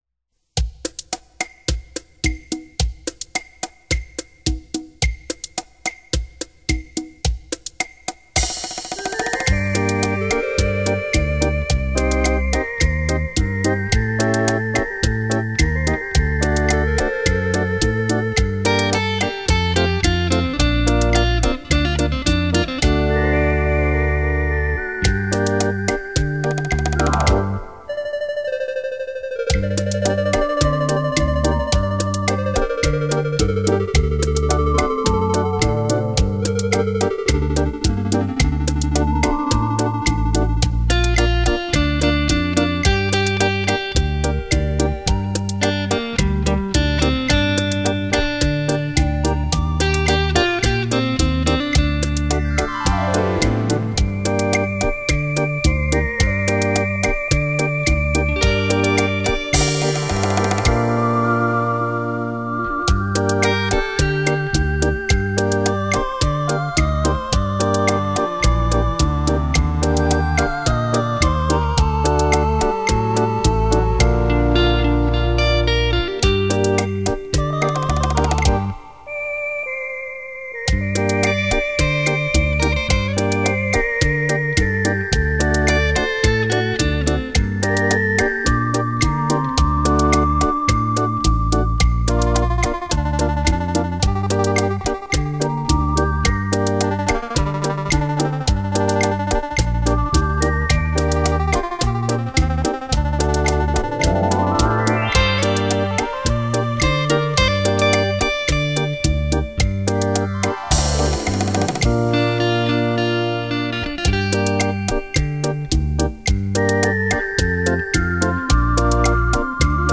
本张专辑系列采用的是，结合了最新音频分离与合成技术和软件音源模拟
冲击力超强。